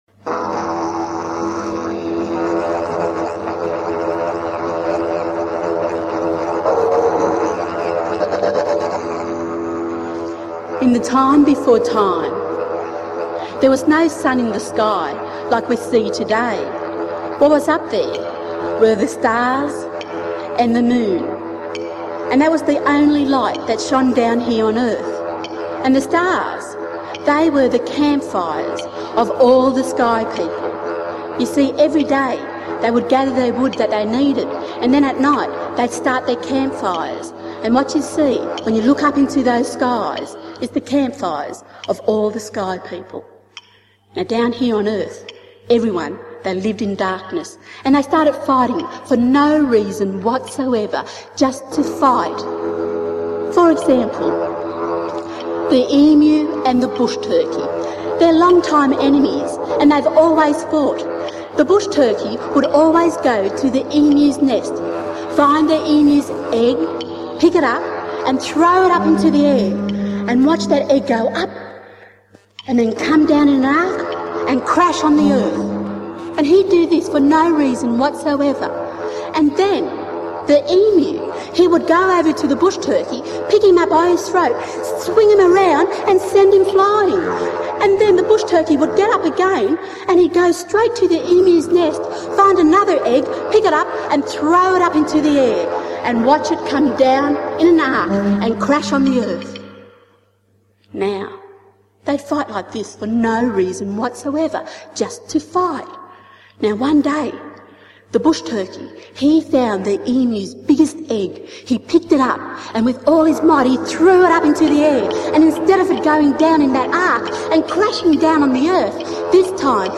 Dreamtime storytelling